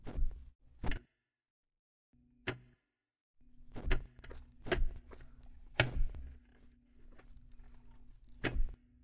Cajuela
描述：Cajuela cerrandose。 Grabado con ZoomH4n
Tag: cajuela 汽车